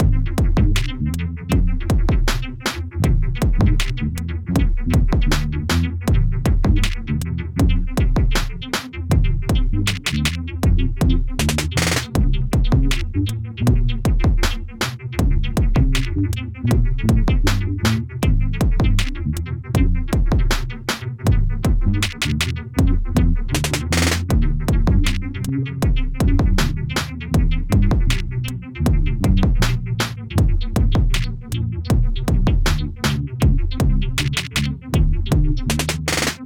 Bass
Drone
Drum Machine